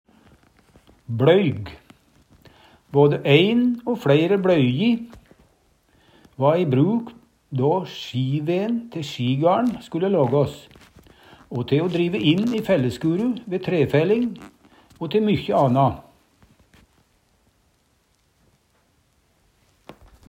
bLøyg - Numedalsmål (en-US)
DIALEKTORD